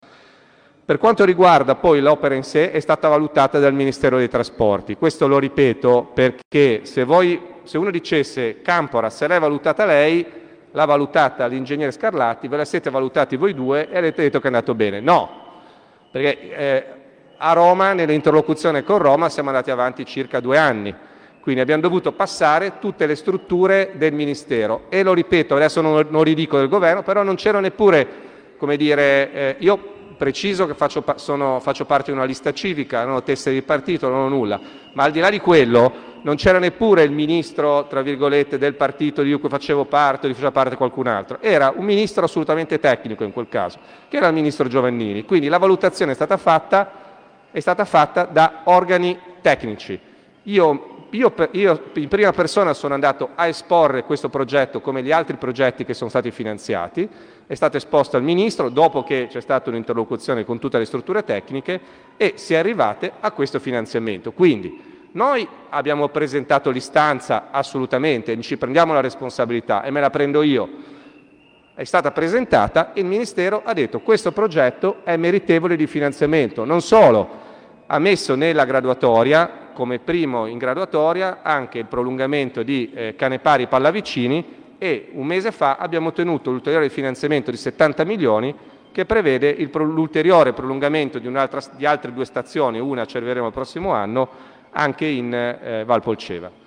Nell’assemblea pubblica di presentazione del progetto Skymetro, svolta il 7 novembre 23 presso il Municipio Bassa Valbisagno, l’assessore Campora, incalzato dalle forti critiche dell’Assemblea sulla sostenibilità del progetto skymetro, disse queste parole: